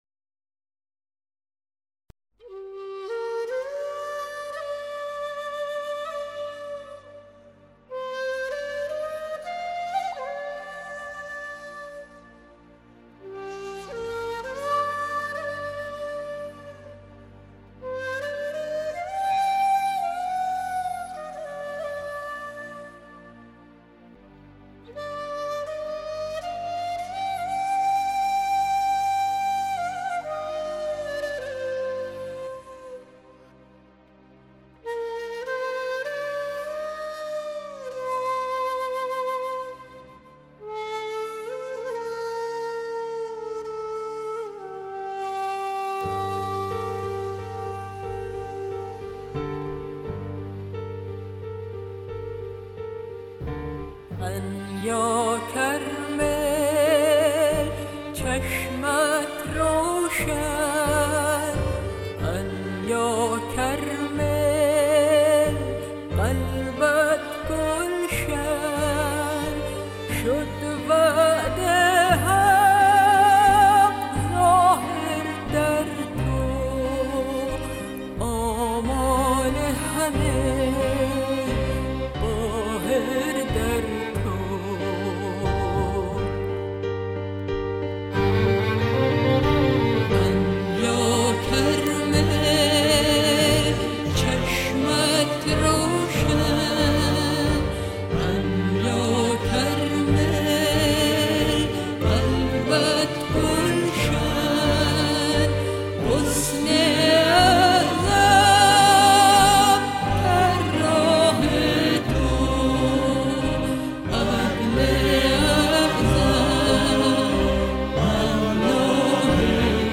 voice is incomparable
most beautiful voice in the world